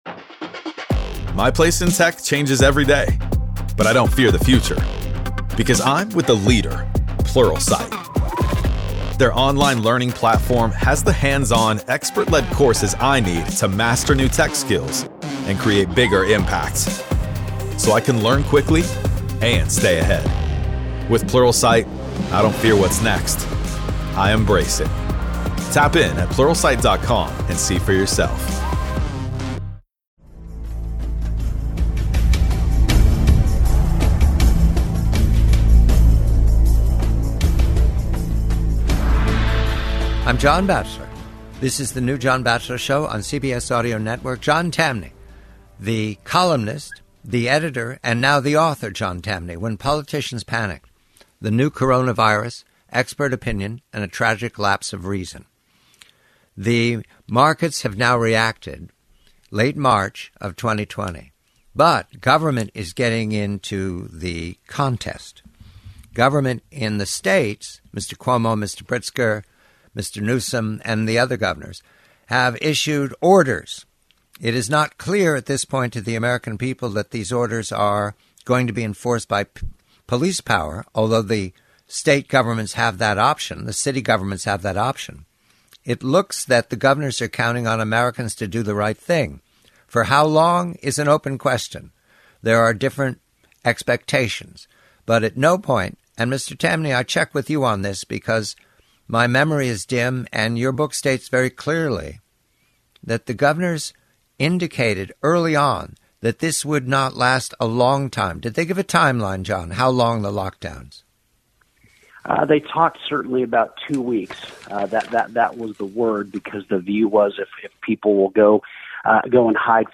The conversation reviews the governors' initial promise of two-week lockdowns, noting that centralized power is rarely relinquished.